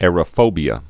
(ârə-fōbē-ə)